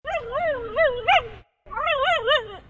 Stray Dog Sound Button - Free Download & Play